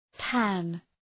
Προφορά
{tæn}